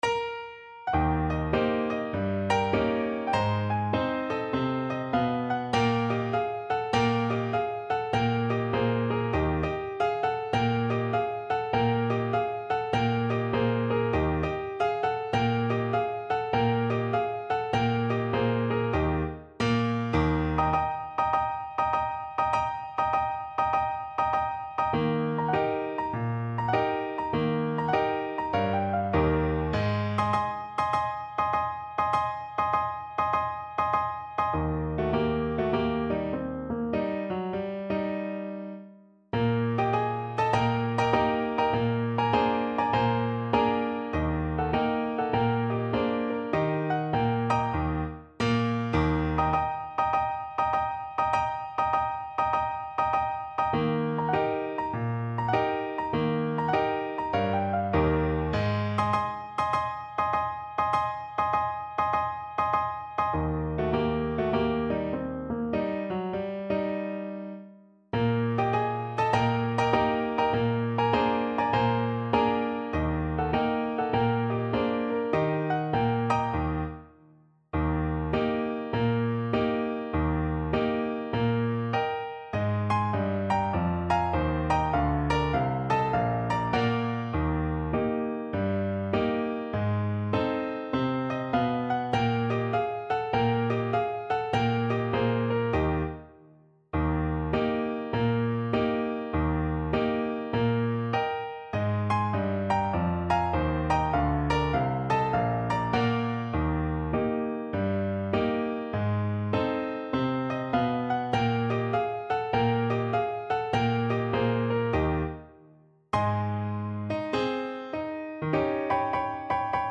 4/4 (View more 4/4 Music)
Jazz (View more Jazz Tenor Saxophone Music)